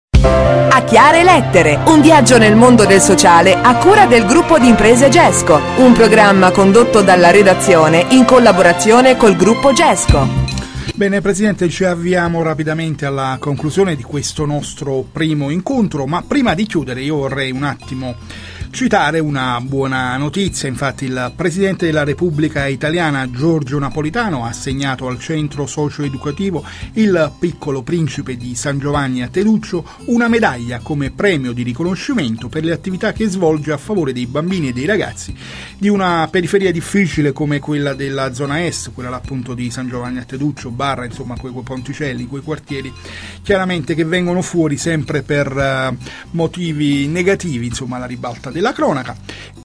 Dalla trasmissione radiofonica "A chiare lettere" Radio Club 91 del 22/09/2009